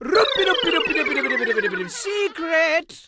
Mode Secretlevel Transition Sound Effect
mode-secretlevel-transition.mp3